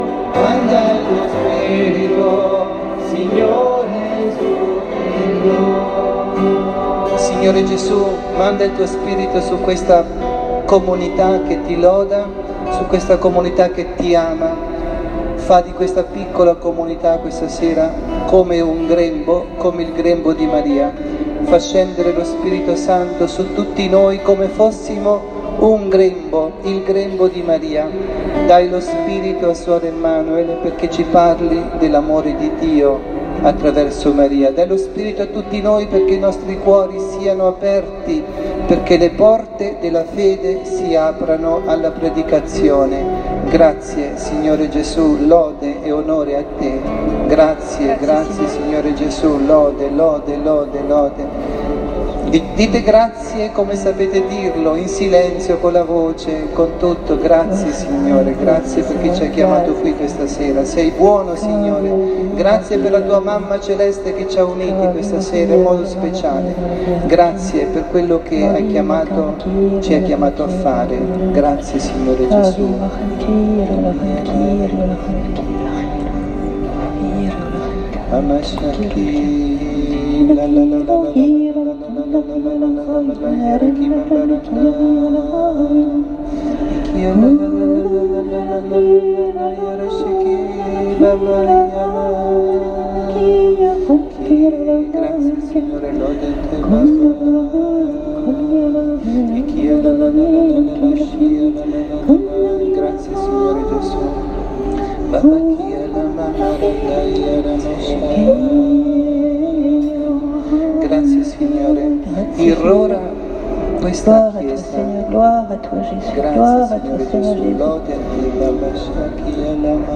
Catechesi e Adorazione
dalla Parrocchia S. Rita – Milano